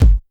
Kick_100.wav